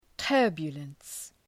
Προφορά
{‘tɜ:rbjələns}